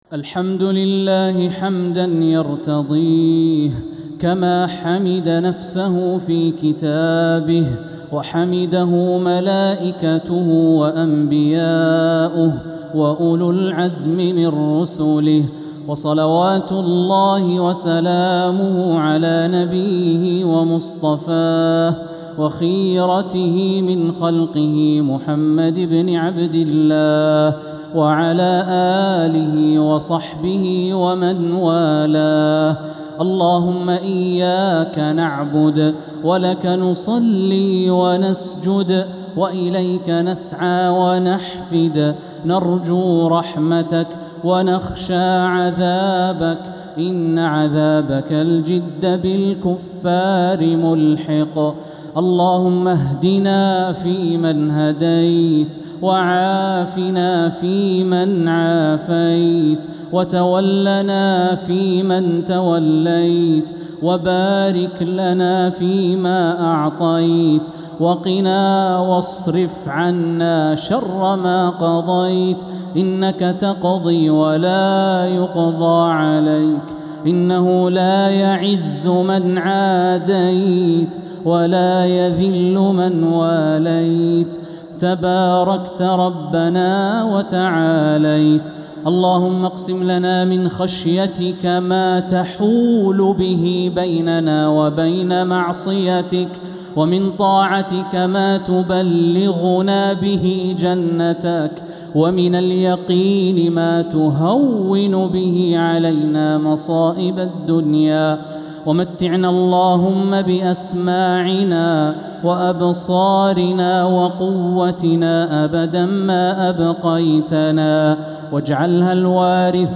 دعاء القنوت ليلة 4 رمضان 1447هـ | Dua 4th night Ramadan 1447H > تراويح الحرم المكي عام 1447 🕋 > التراويح - تلاوات الحرمين